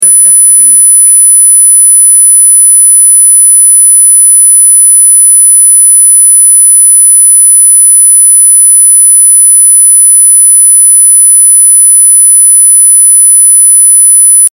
🧠🌌 🌿 Cette fréquence, issue des protocoles de Royal Rife, est utilisée pour stimuler la clarté mentale et apporter une meilleure concentration. ⚡ Écoutez-la quelques minutes par jour, au casque si possible, pour ressentir un apaisement progressif de l’esprit et une plus grande fluidité dans vos pensées.